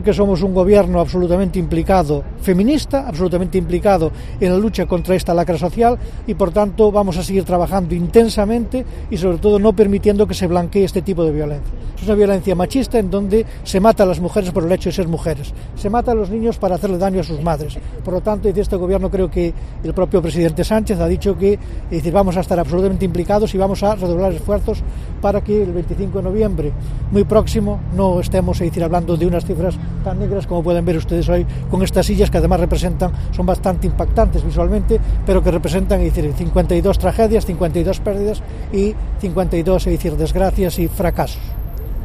Pedro Blanco, delegado del gobierno en Galicia
El acto celebrado esta mañana en A Coruña, con motivo del Día Internacional por la Eliminación de la Violencia sobre las Mujeres que se conmemora cada 25 de noviembre, comenzaba con la colocación de 52 sillas vacías en representación de las mujeres asesinadas en lo que va de año.